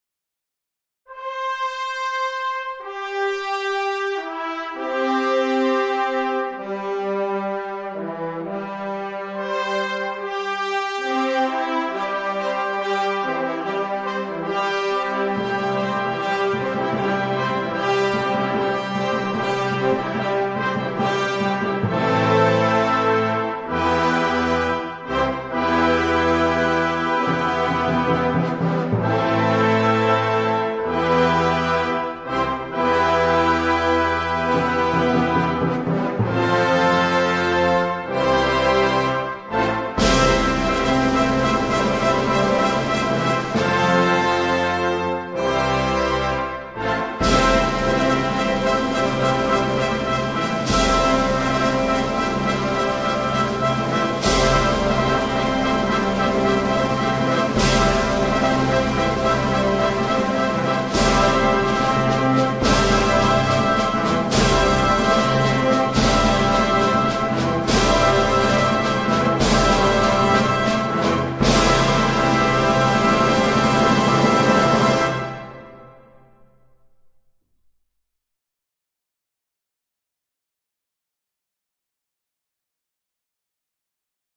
Fanfára z opery Libuše.mp3